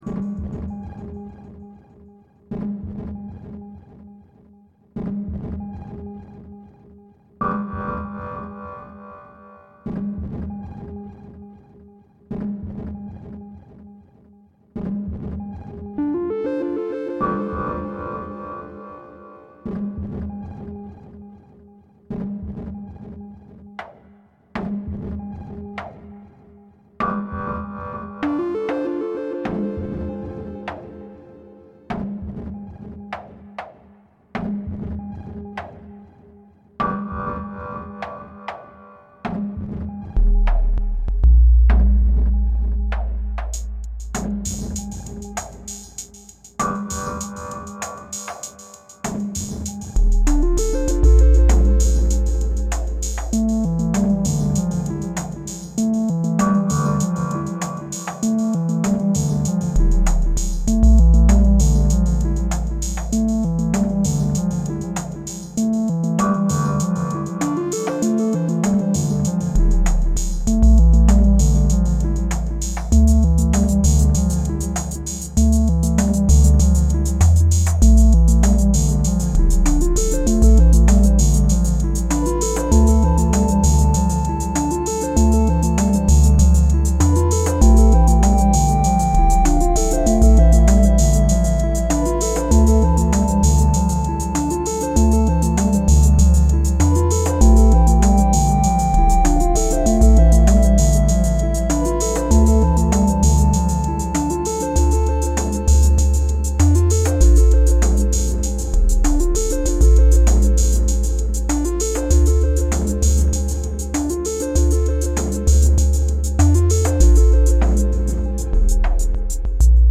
Here’s a little ambient jam, trying out the new features:
So much melody, amazing job and really shows off what the new OS can do.
Yes it’s all internal sequencing, muting and unmuting pads.
SY Raw for pad sound
2 x SY Chip for arpeggios